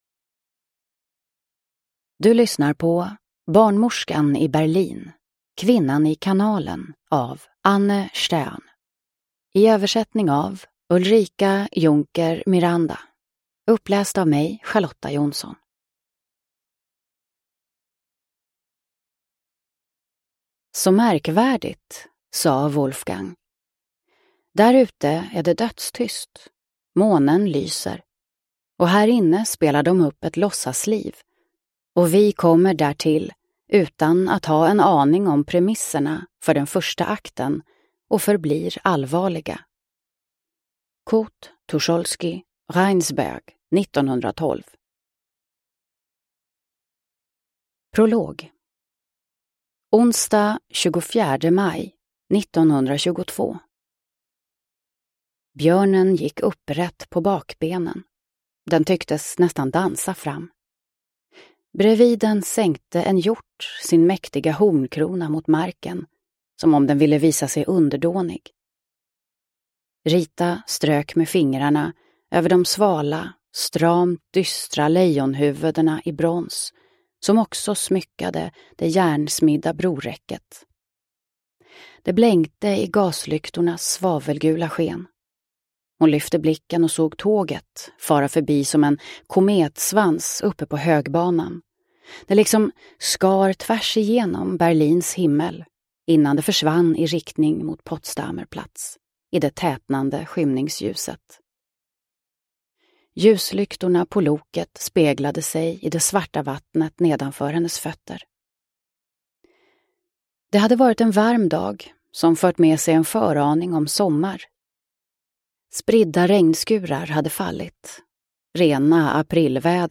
Kvinnan i kanalen – Ljudbok – Laddas ner